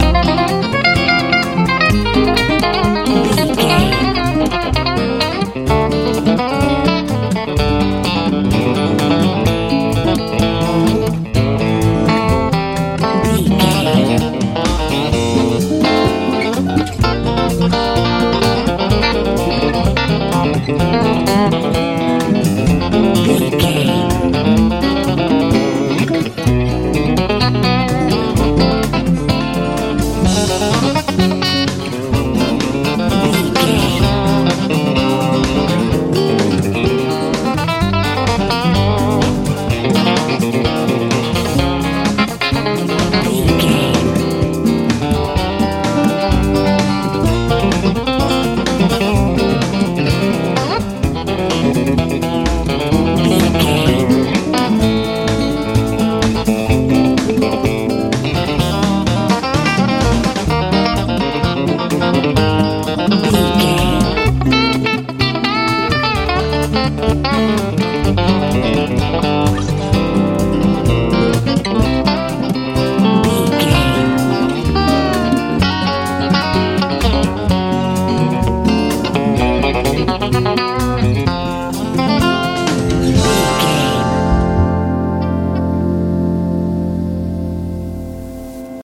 country picken feel
Ionian/Major
driving
bass guitar
drums
acoustic guitar
electric guitar
southern
tension